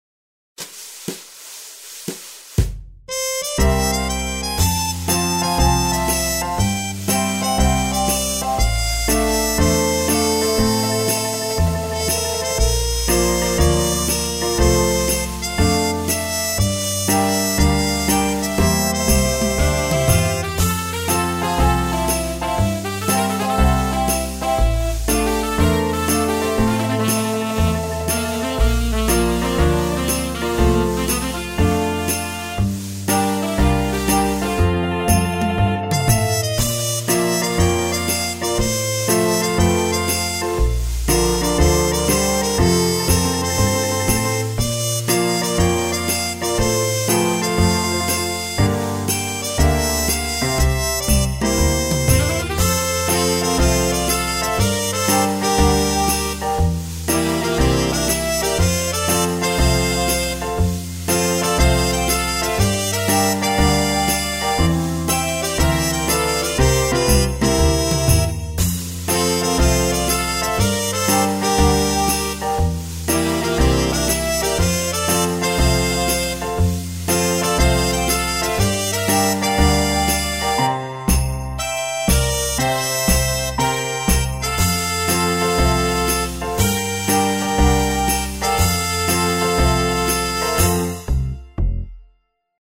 カントリーショート穏やか